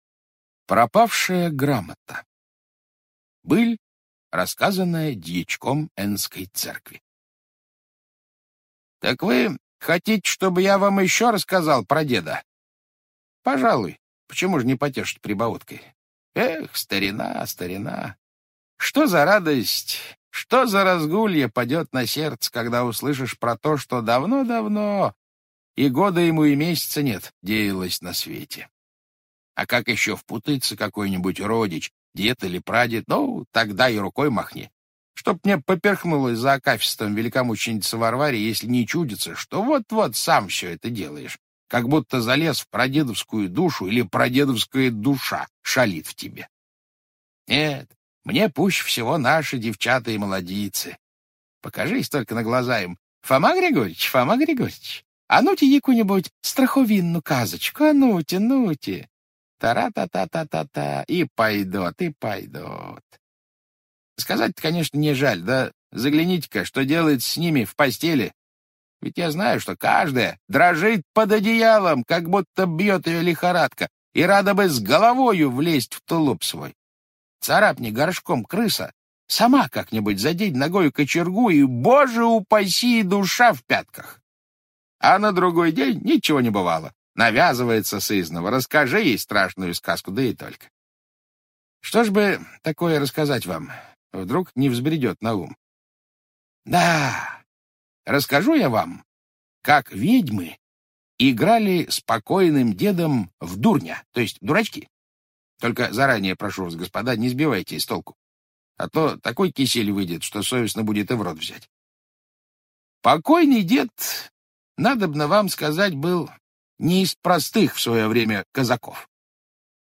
Пропавшая грамота - аудио рассказ Николай Гоголь - слушать онлайн